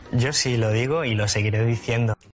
Palabras de Alberto Garzón en la Sexta